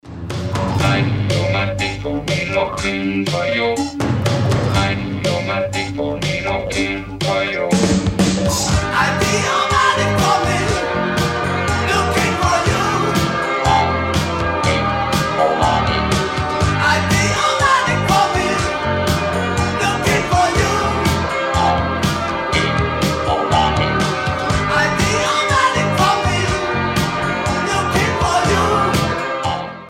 facevano uso massiccio di elettronica e sintetizzatori